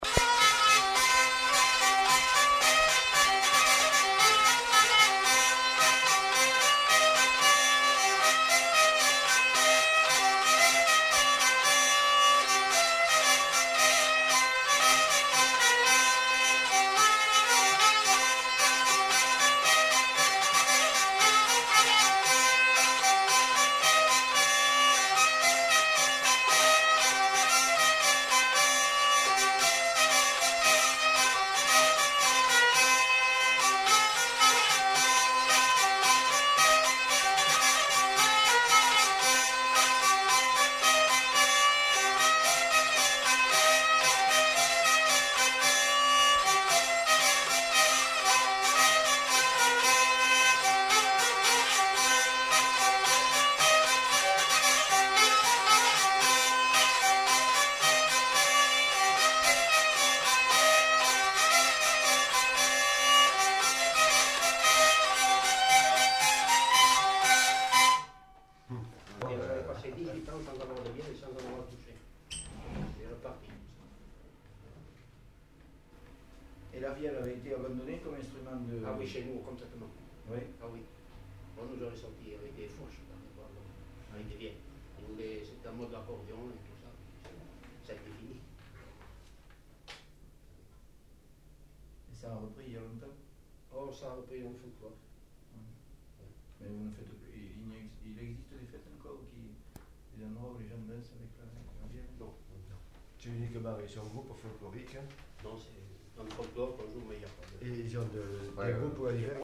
Lieu : Pyrénées-Atlantiques
Genre : morceau instrumental
Instrument de musique : vielle à roue
Danse : polka
Notes consultables : La séquence est suivie d'une brève discussion.